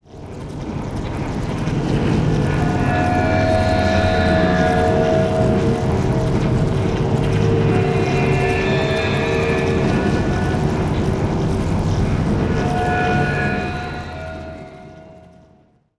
Drone
Another great sound design tool for creating sonic textures and abstract movement:
Workstation-Drone.mp3